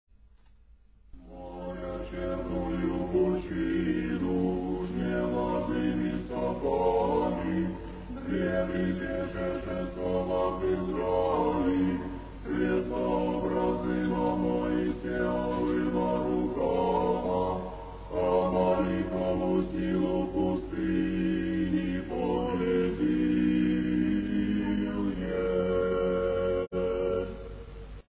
24 сентября 2002 г. Перезаписаны с кассеты все файлы октоиха.
Ирмос